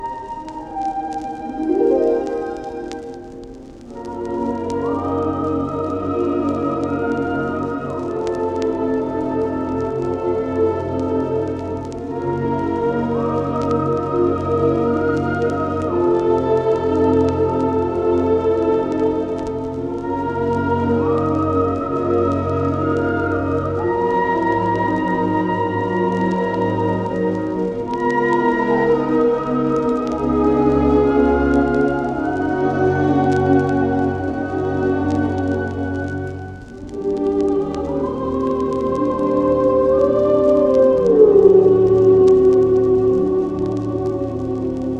オーケストラによる美しいメロディと効果的に現れる歌声、暗さも魅力的です。
Stage & Screen, Soundtrack　USA　12inchレコード　33rpm　Mono